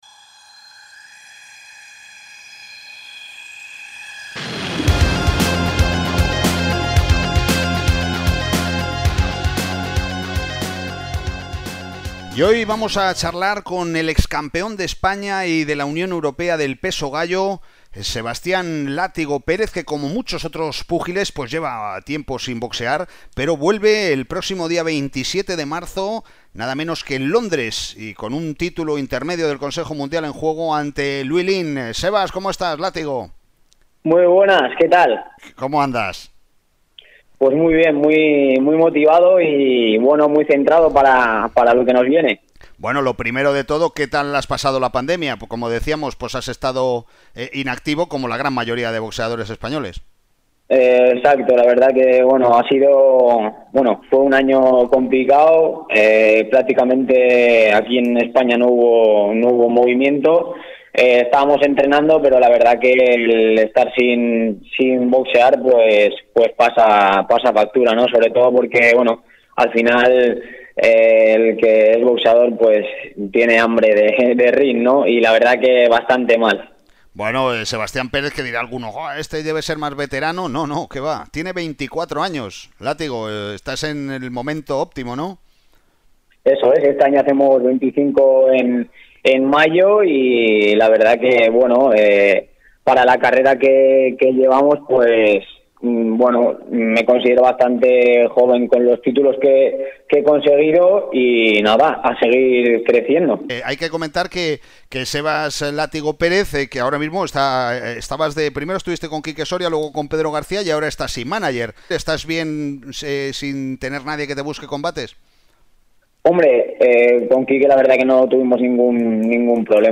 entrevistados radiofónicamente